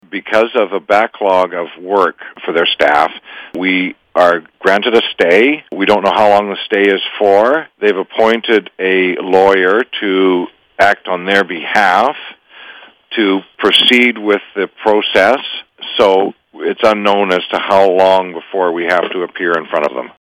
Mayor Bill McKay says the stay from the province is just temporary.